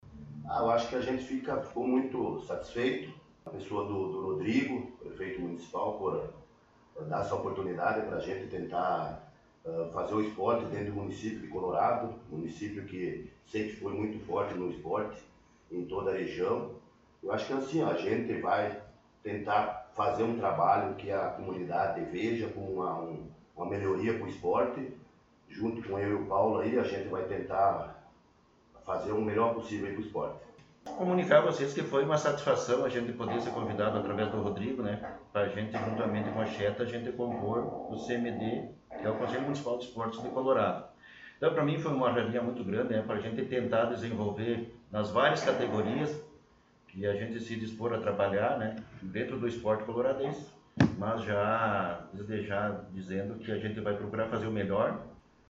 Coordenadores do Esporte Municipal concederam entrevista